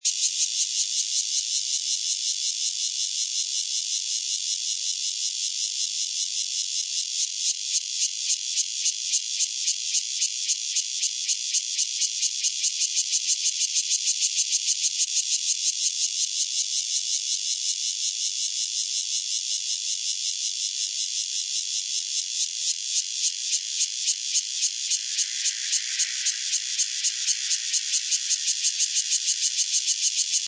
澄清湖高砂熊蟬2.mp3
高砂熊蟬 Cryptotympana takasagona
錄音地點 高雄市 鳥松區 澄清湖
錄音環境 雜木林
雄高砂熊蟬呼喚歌聲
收音: 廠牌 Sennheiser 型號 ME 67